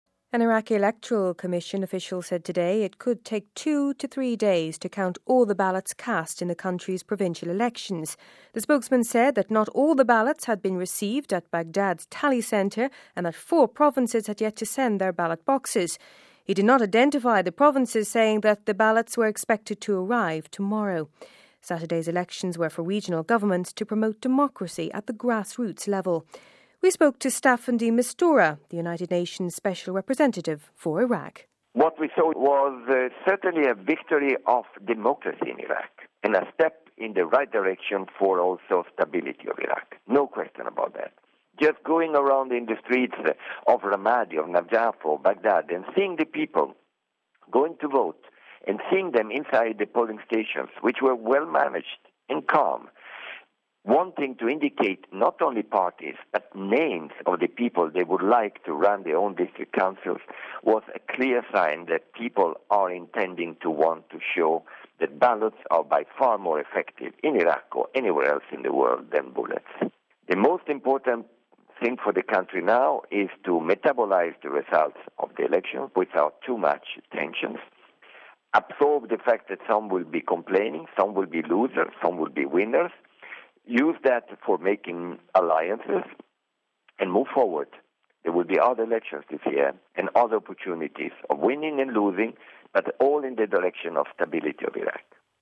(02 Feb 09 - RV) An Iraqi electoral commission official said today it could take two to three days to count all the ballots cast in the country's provincial elections. We spoke to Staffan De Mistura, the United Nations Special Representative for Iraq...